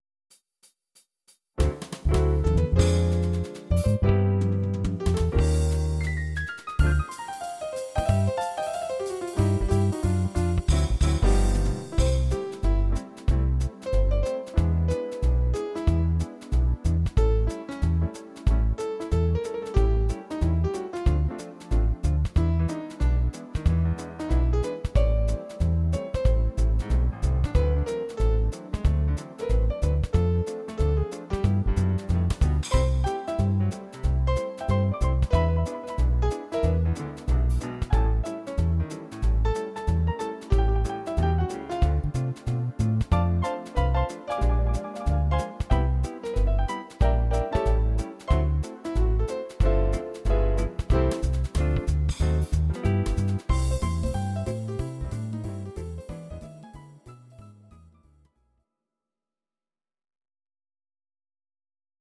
These are MP3 versions of our MIDI file catalogue.
Please note: no vocals and no karaoke included.
Bar Piano